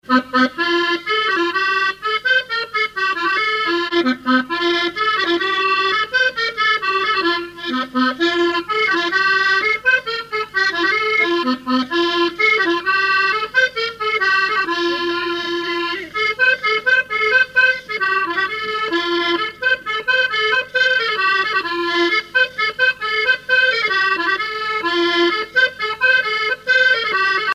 danse : quadrille : avant-quatre
Musique du quadrille local
Pièce musicale inédite